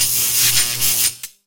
Electric Spark
A bright electric spark arcing with sharp crackle and brief sizzle
electric-spark.mp3